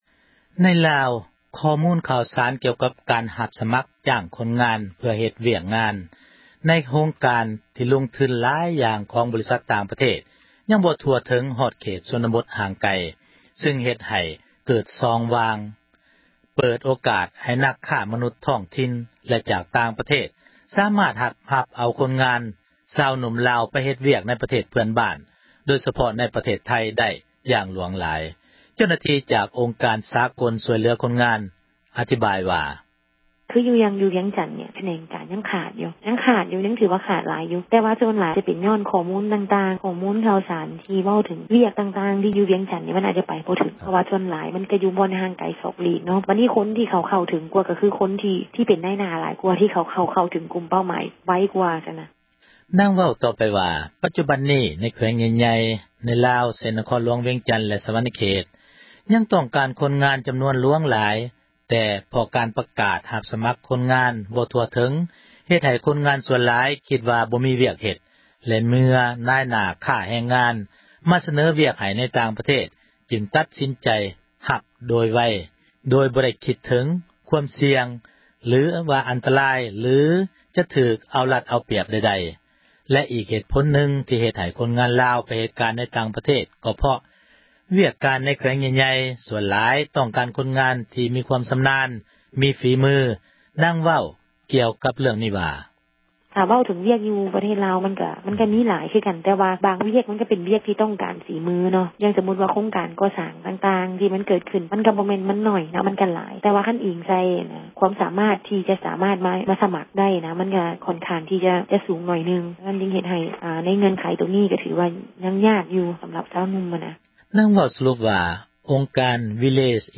ເຈົ້າໜ້າທີ່ ຈາກອົງການ ສາກົນ ຊ່ວຍເຫລືອ ຄົນງານ ອະທິບາຍ ວ່າ: